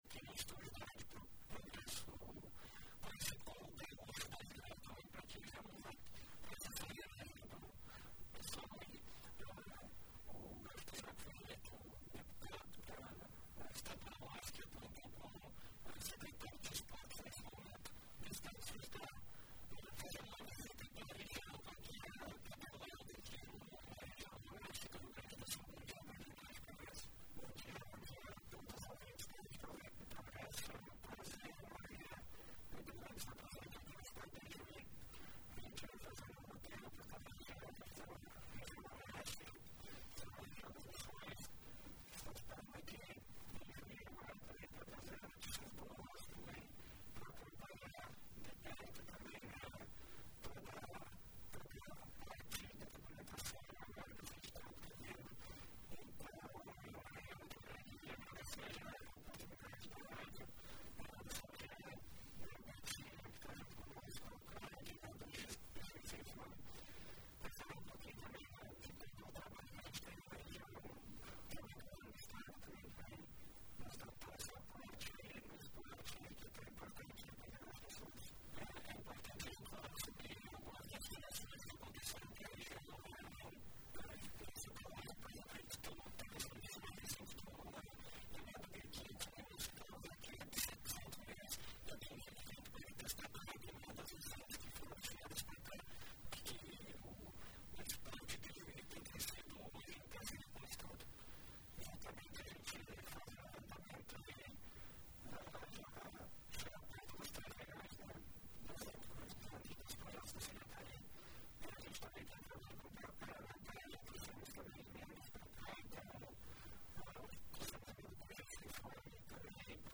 Em entrevista hoje, 14, na Rádio Progresso, o Secretário Estadual de Esporte e Lazer, Juliano Franczak, o Gaúcho da Geral, disse estar na região com o objetivo de ajustar e viabilizar os recursos do projeto Avançar no Esporte.